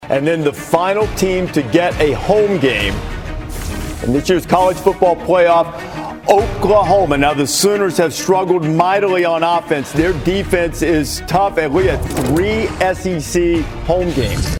Here is how the selection sounded on ESPN.